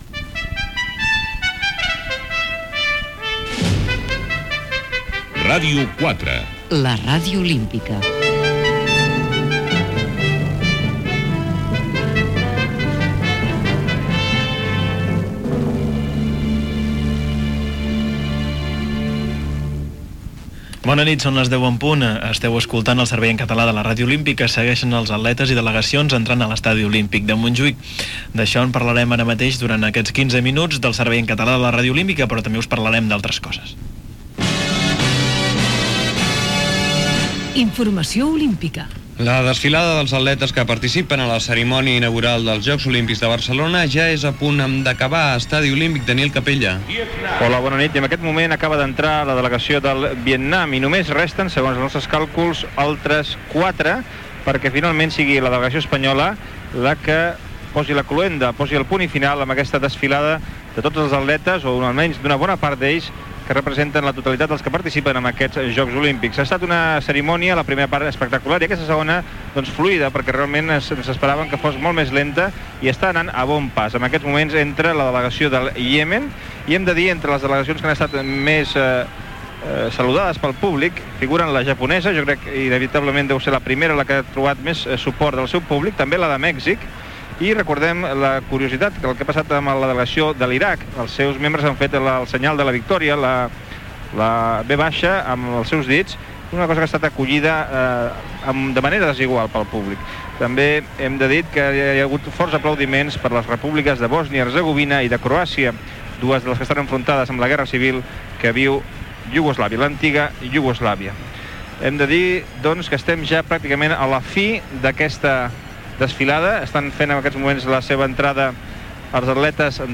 transmissió des de dins de l'estadi quan comencen els discursos oficials de Pasqual Maragall i Juan Antonio Samarach
Gènere radiofònic Informatiu